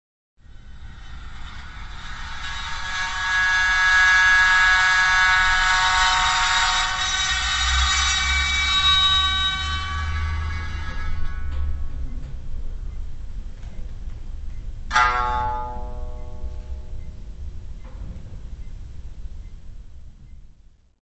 Music Category/Genre:  Classical Music
pour shamisen et clavecin
for shamisen and harpsichord